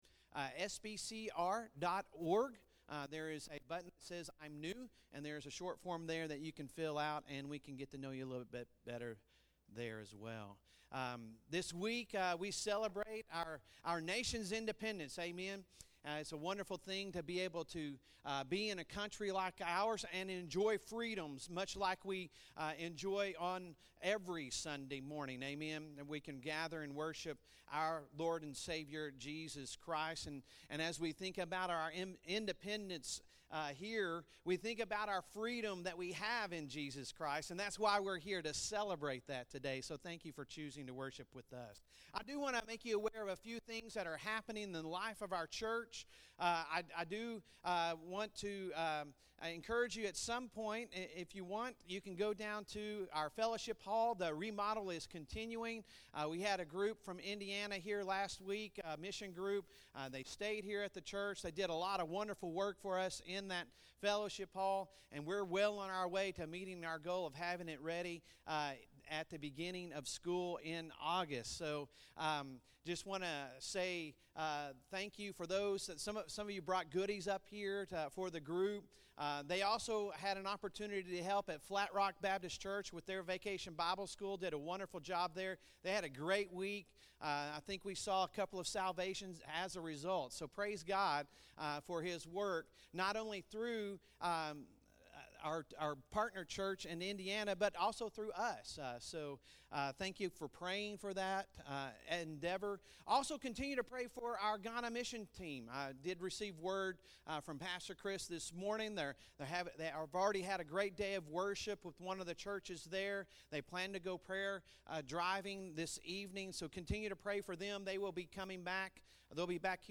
Sunday Sermon July 2, 2023